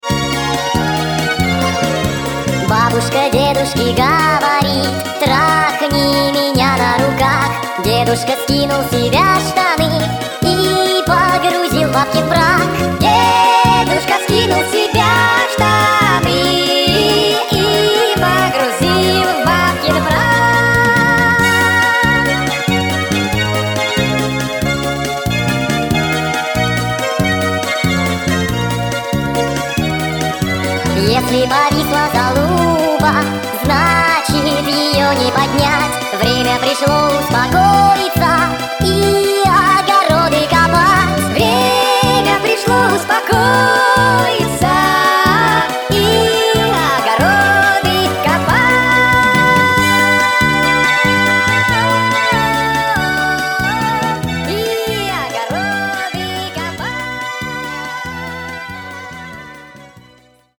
смешной голос
цикличные